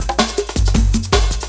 06 LOOP12 -L.wav